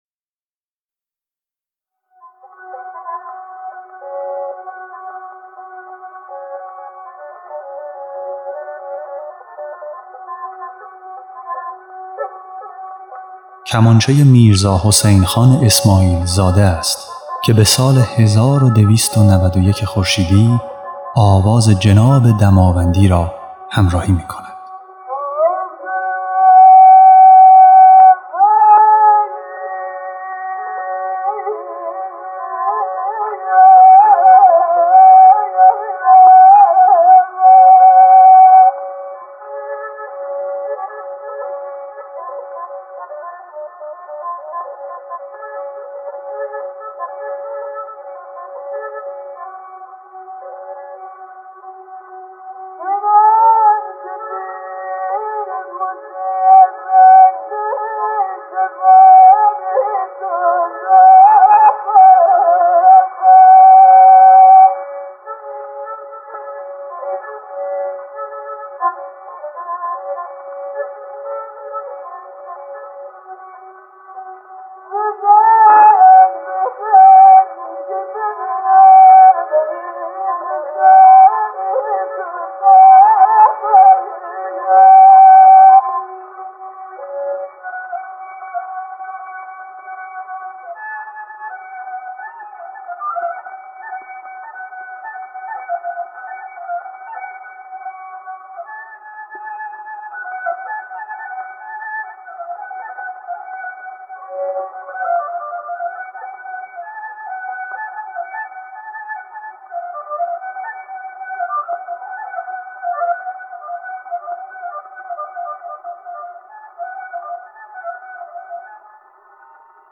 خواننده
نوازنده کمانچه